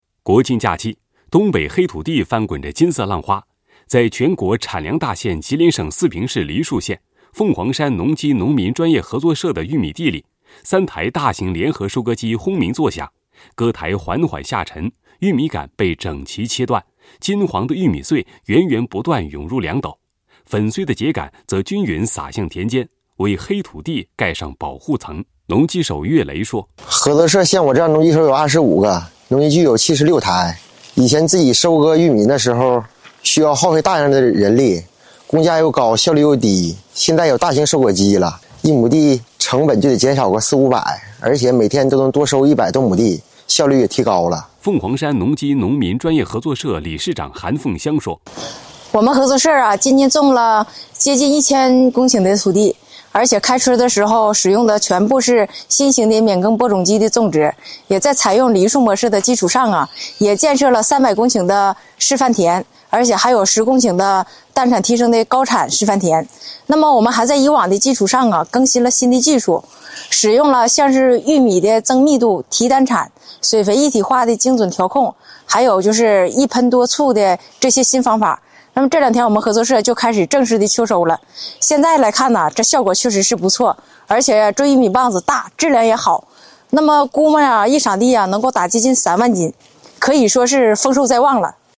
在全国产粮大县吉林省四平市梨树县，凤凰山农机农民专业合作社的玉米地里，3台大型联合收割机轰鸣作响。割台缓缓下沉，玉米秆被整齐切断，金黄的玉米穗源源不断涌入粮斗，粉碎的秸秆则均匀撒向田间，为黑土地盖上“保护层”。